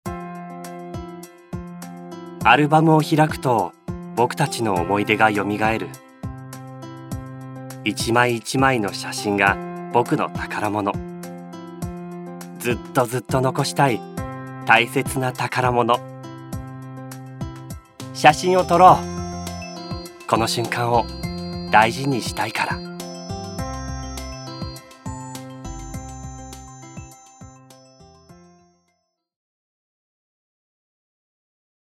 ボイスサンプル
カメラCM